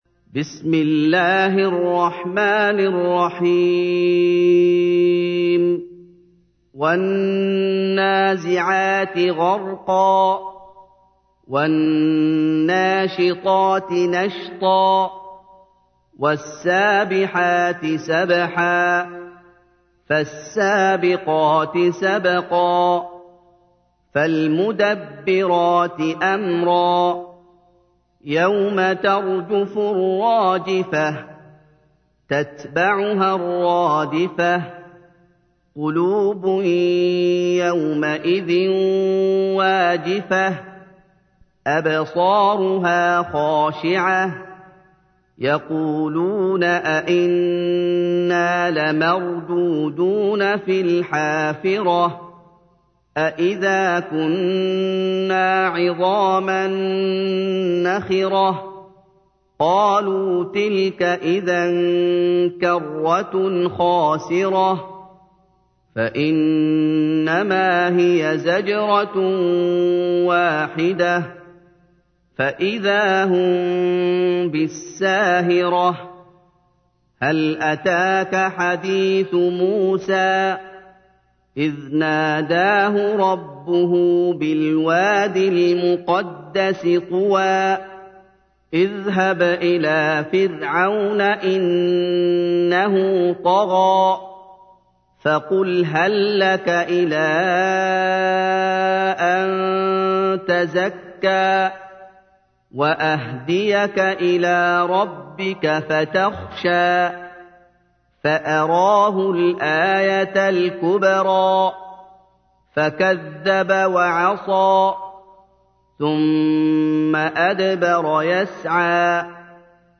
تحميل : 79. سورة النازعات / القارئ محمد أيوب / القرآن الكريم / موقع يا حسين